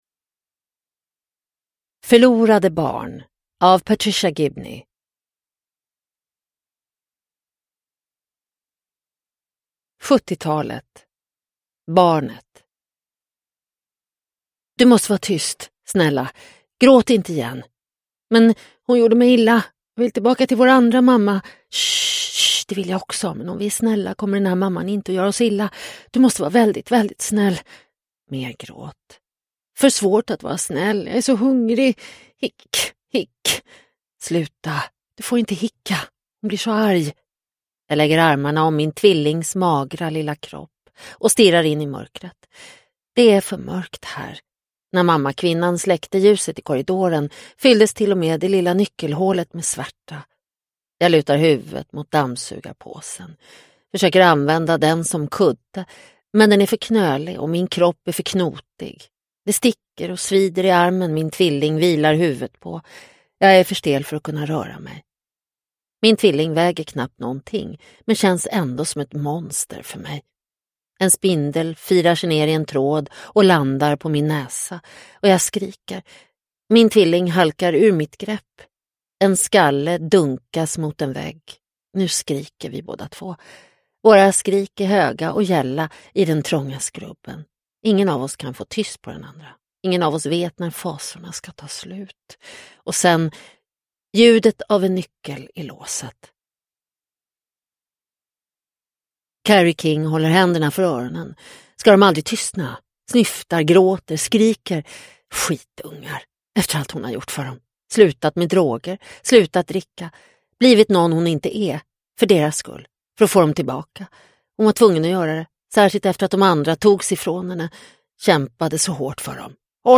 Förlorade barn – Ljudbok – Laddas ner